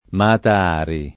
vai all'elenco alfabetico delle voci ingrandisci il carattere 100% rimpicciolisci il carattere stampa invia tramite posta elettronica codividi su Facebook Mata Hari [indon. m # ta h # ri ; italianizz. m # ta # ri ] pseud.